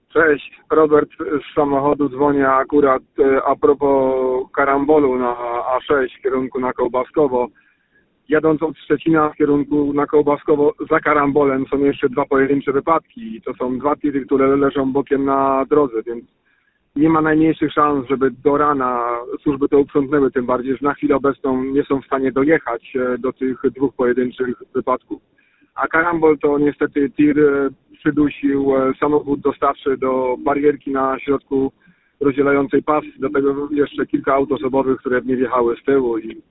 Słuchacze Twojego Radia ostrzegają: